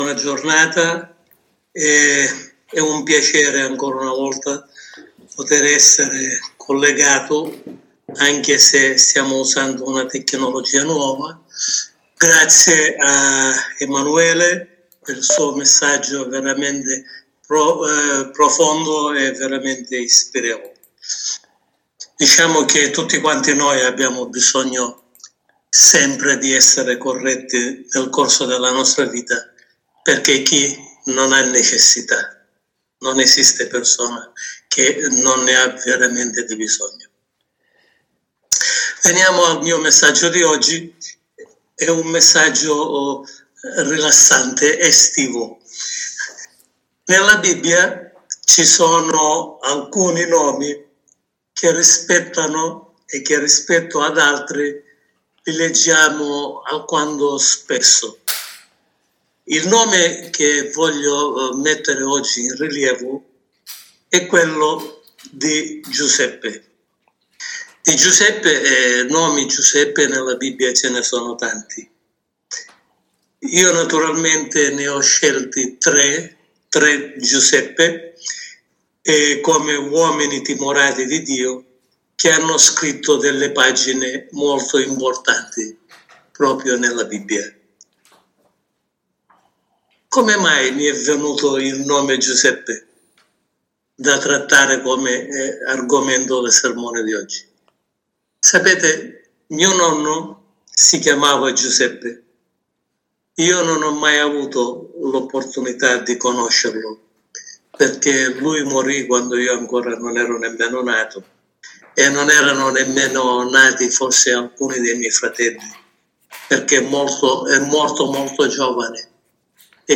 Sermone pastorlae